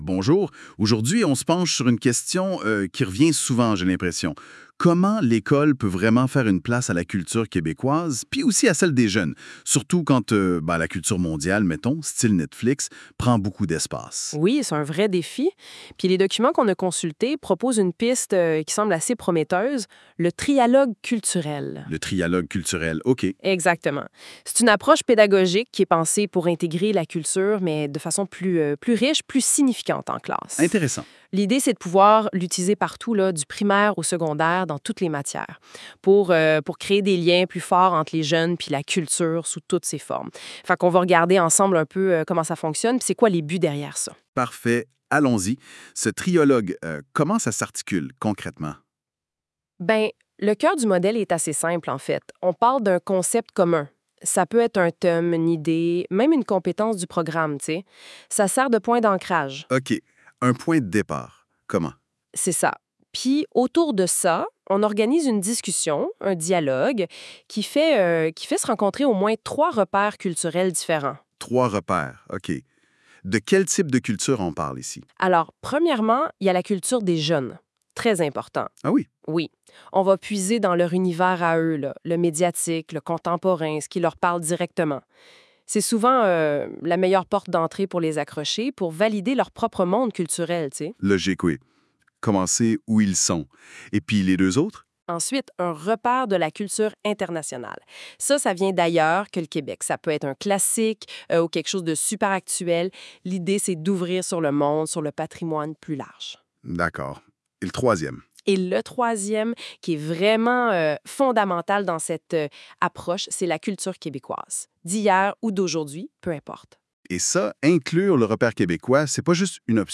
Balado au sujet du Trialogue (généré par l’IA avec Notebook)